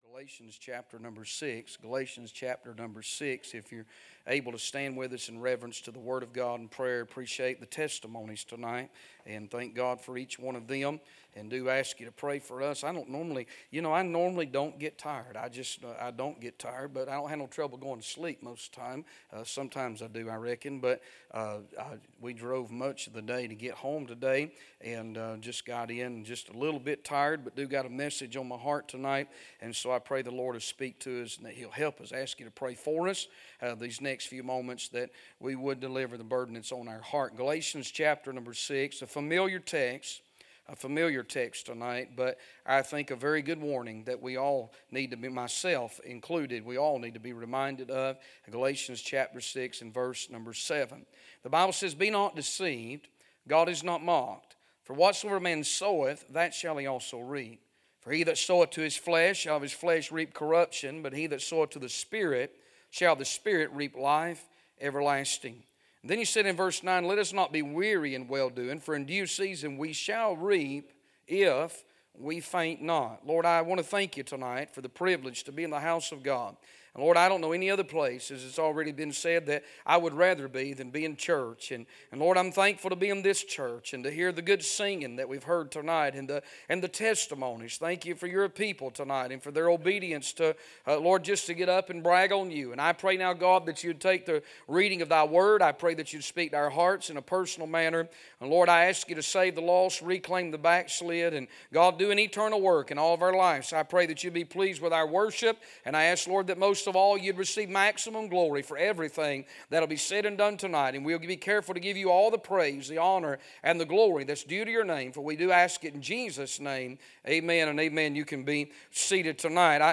A sermon preached Wednesday Evening, on April 9, 2025.